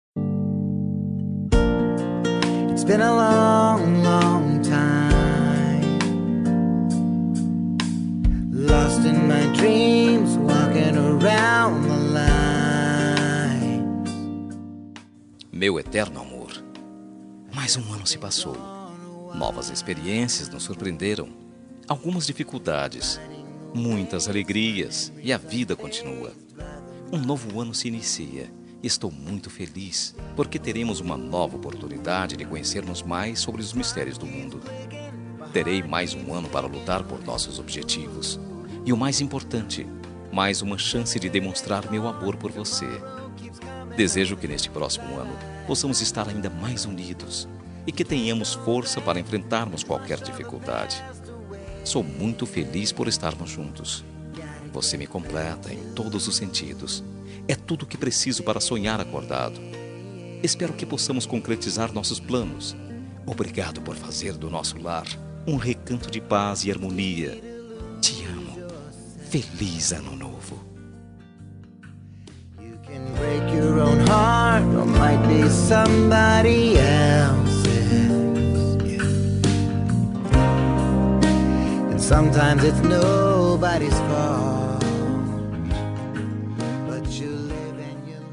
Ano Novo – Romântica – Voz Masculina – Cód: 6424